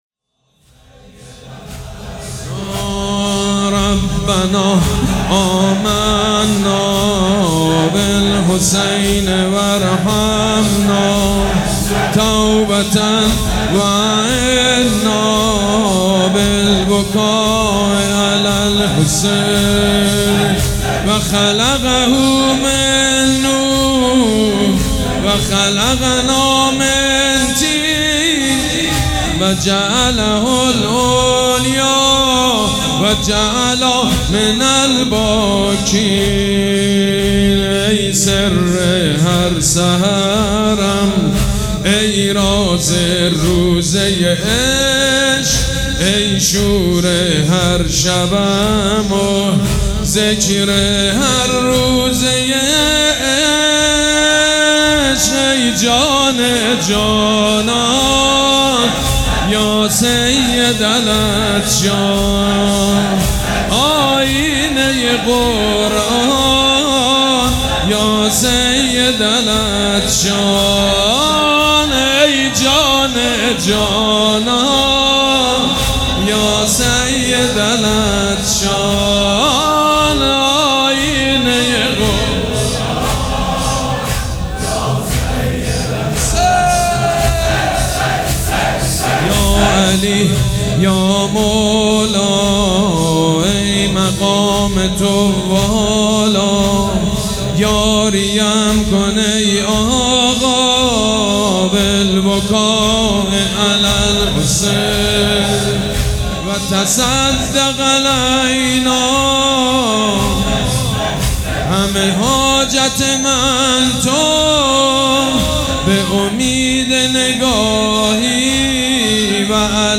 هیئت ریحانه الحسین سلام الله علیها
مداح
حاج سید مجید بنی فاطمه